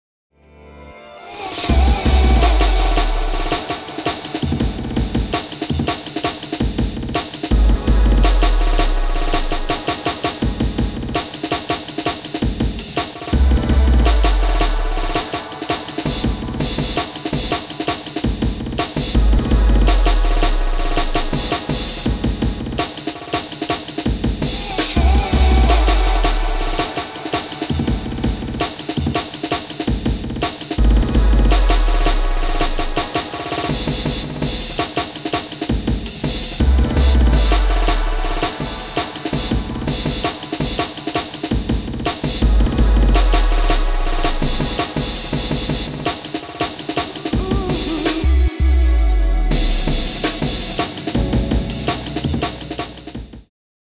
The M8 makes it trivially easy to program in loads of retriggers like this, here’s a clip from something I’m working on with slices going massively over the top with RET programming